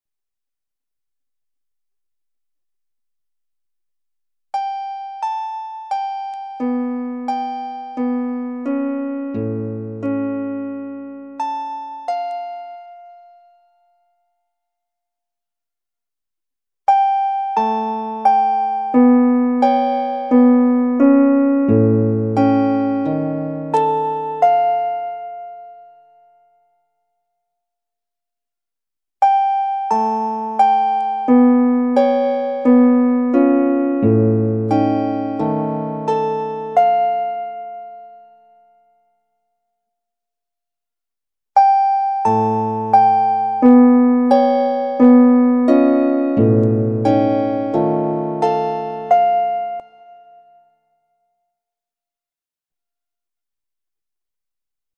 Now listen to the four calcium sites played together, first one site, then the first and second sites together. The third and fourth sites are then added in sequence.